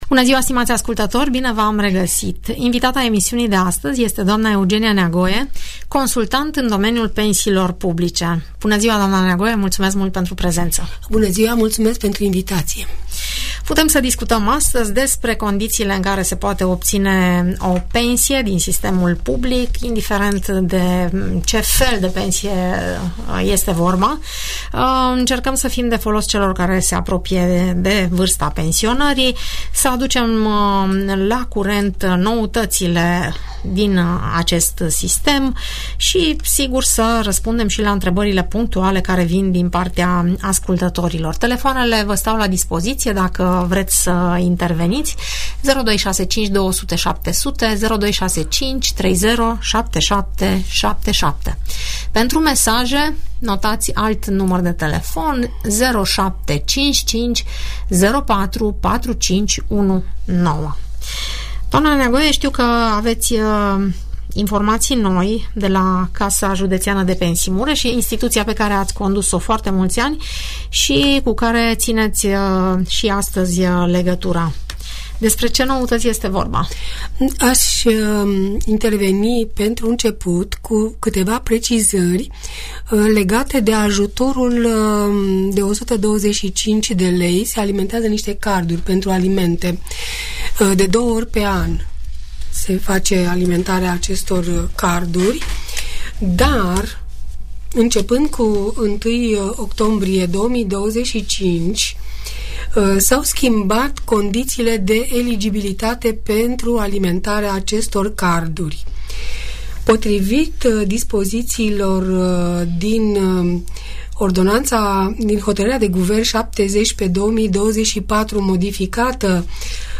Mureș pe tema pensiilor publice Întrebări și răspunsuri despre toate tipurile de pensii, în emisiunea "Părerea ta" de la Radio Tg Mureș. în dialog cu ascultătorii.